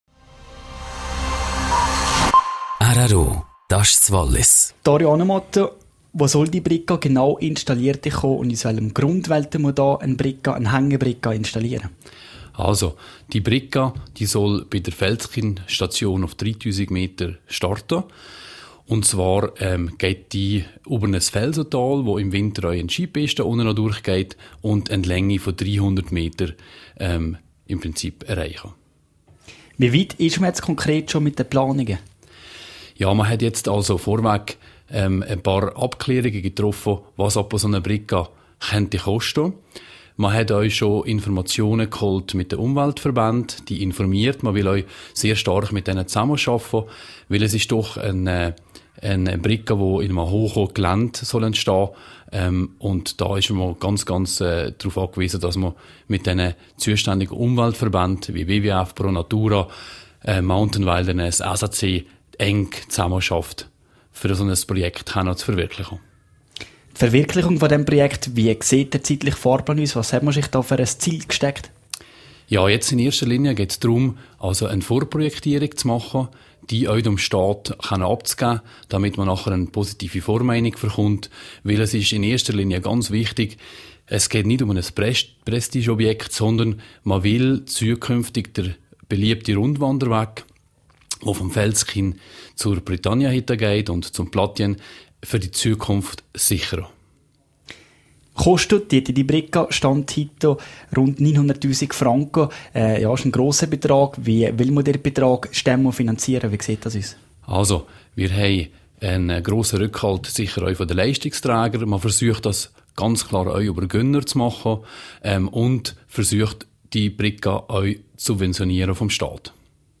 Interview zum Thema (Quelle: rro)